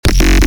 bass house one shots
Vortex_Basses_F#_16